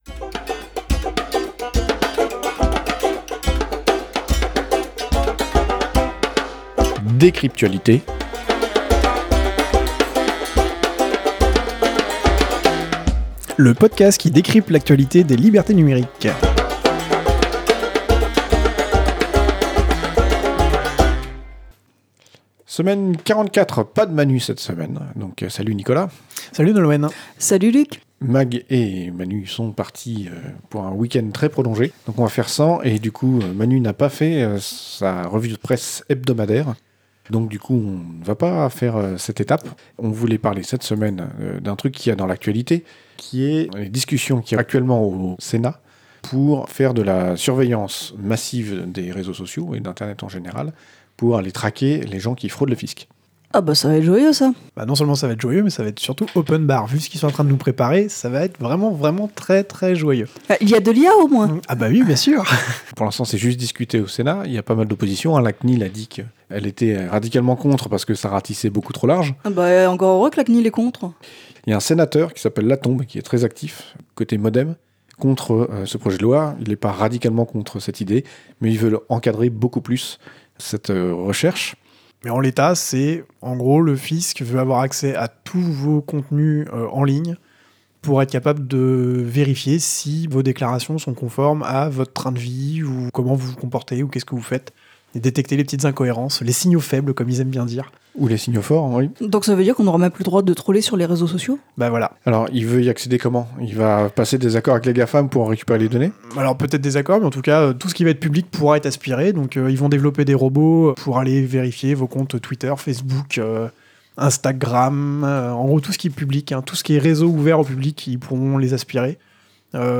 Revue de presse pour la semaine 44 de l’année 2019